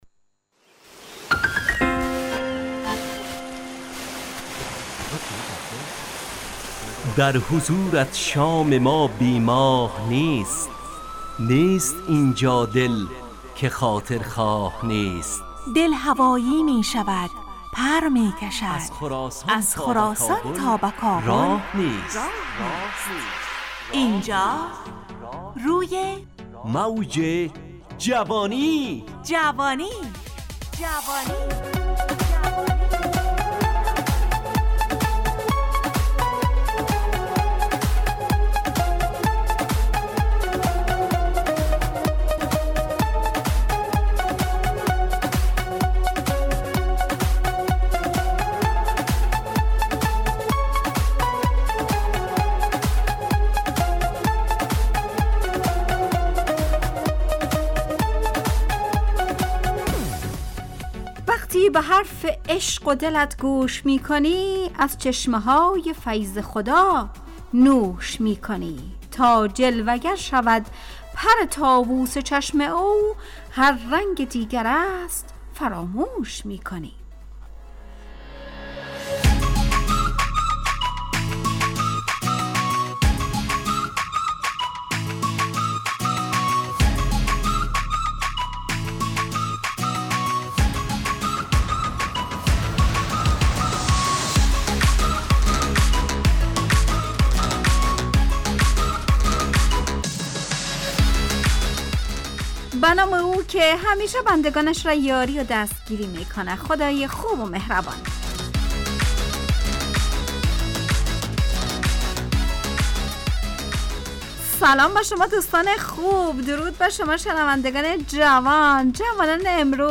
روی موج جوانی، برنامه شادو عصرانه رادیودری.
همراه با ترانه و موسیقی مدت برنامه 70 دقیقه . بحث محوری این هفته (شکست) تهیه کننده